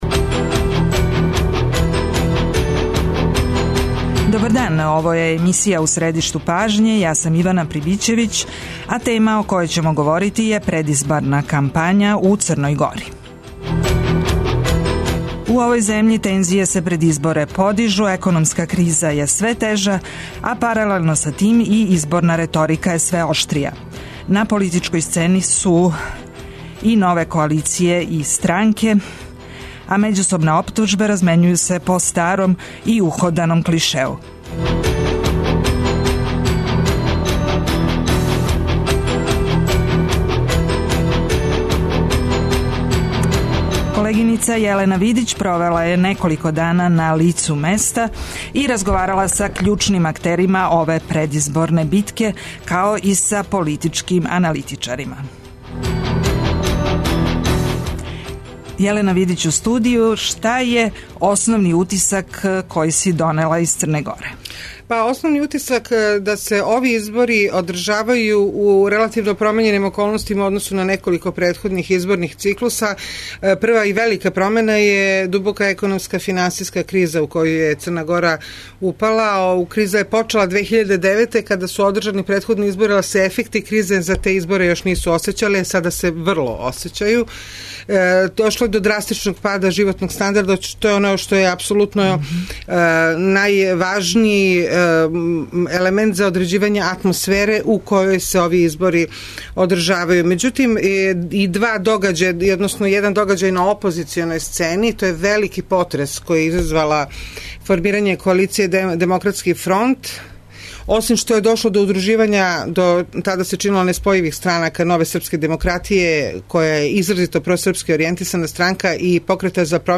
Саговорници емисије су и аналитичари који објашњавају стање на политичкој сцени и у свакодневном животу Црне Горе.